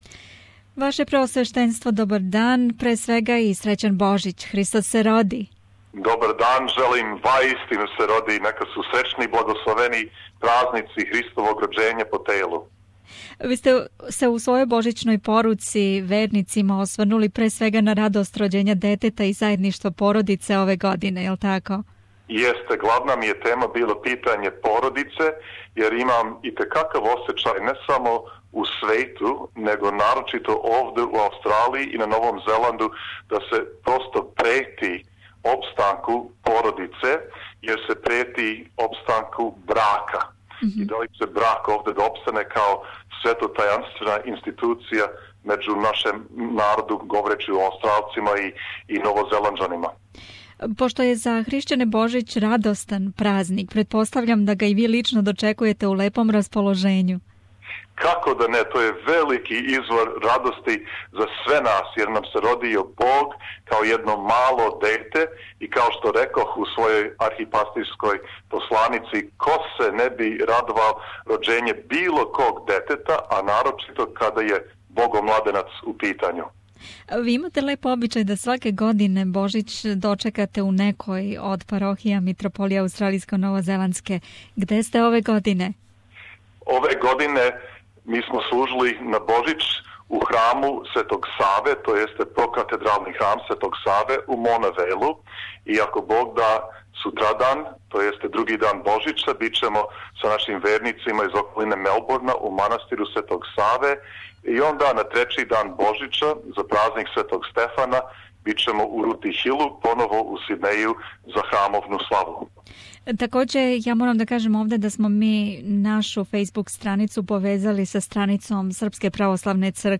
In the spirit of Chiristmas we have talked to His Hollines Archbishop of the Metropolitanate Australia and New Zealand Irinej.